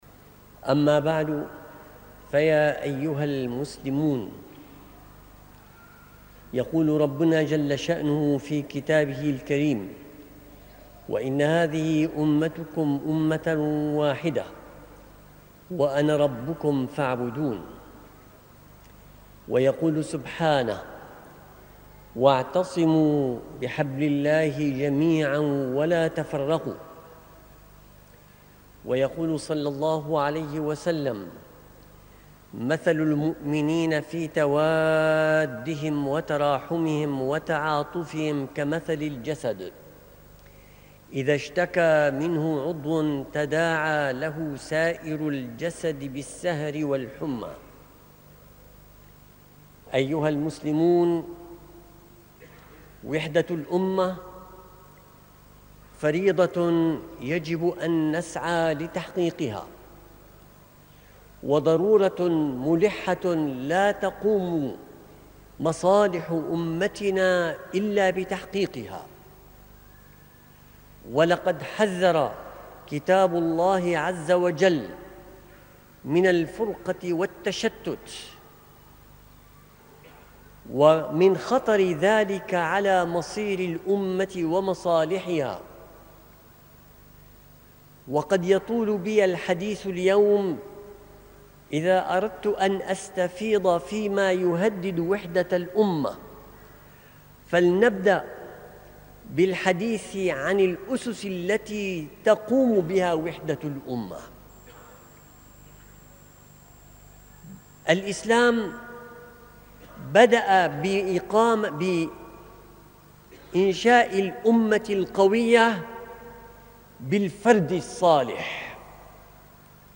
خطبة